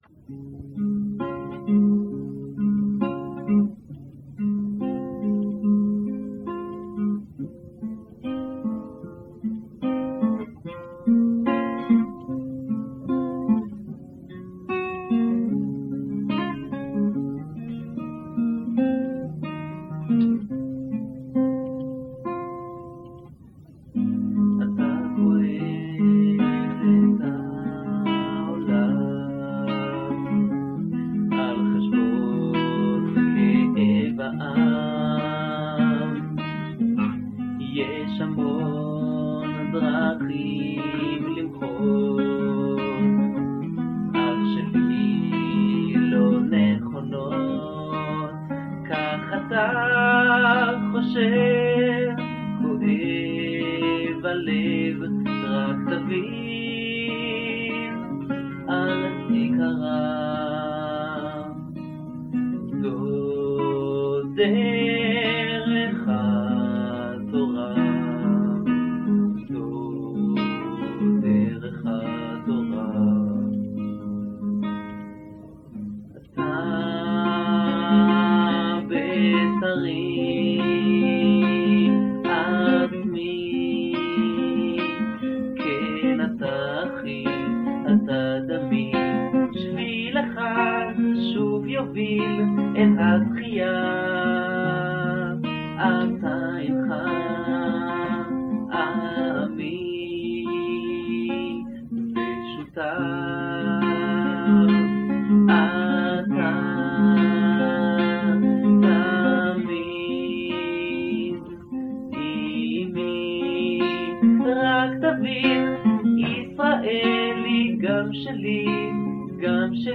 Guitar accompaniment